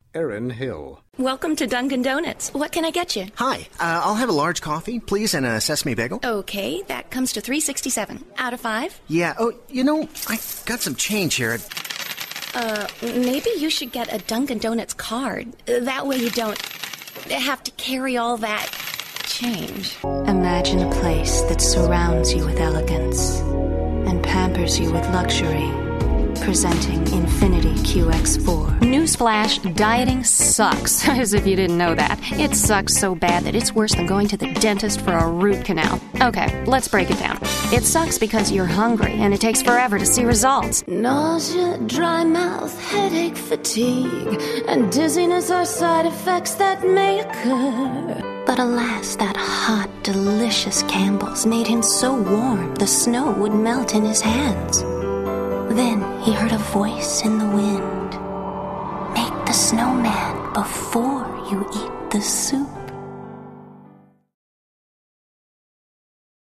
commercial : women